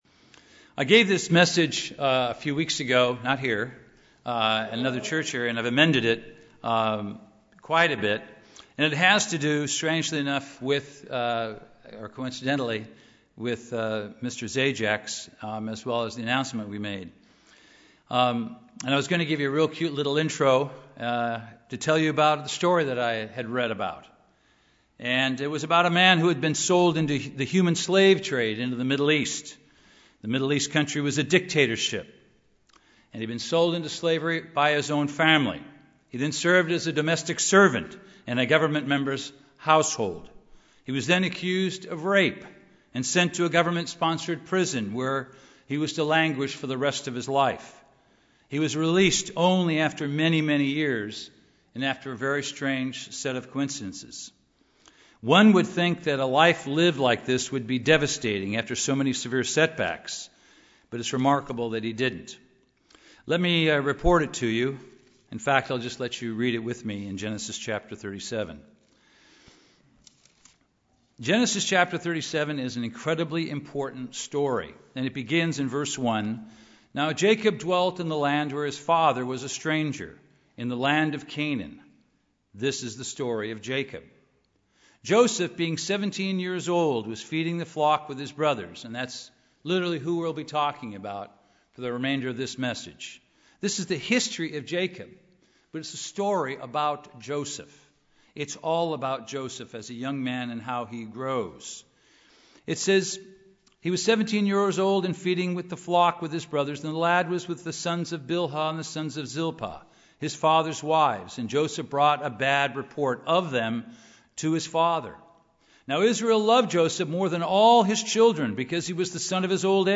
This sermon examines some important lessons for us from the remarkable life of Joseph, one of the sons of Israel.
Given in Los Angeles, CA